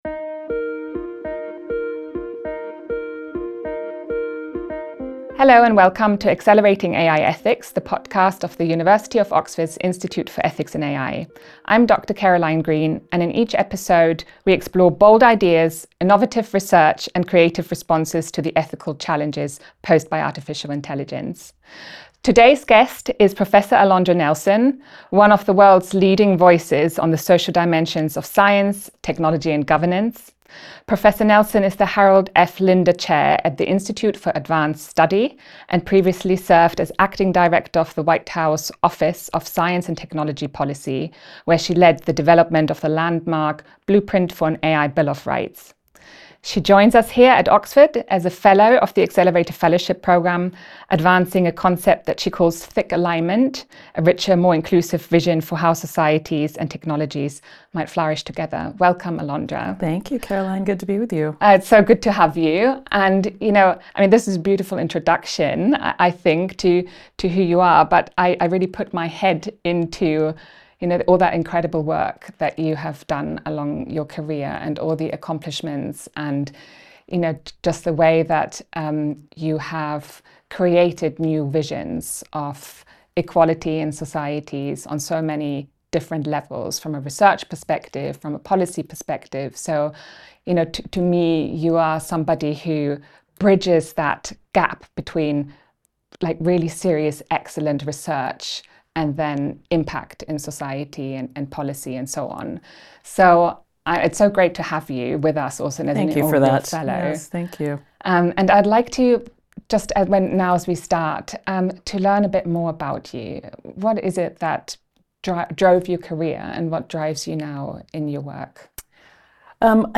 Thick Alignment and the Future of AI Governance: A Conversation with Professor Alondra Nelson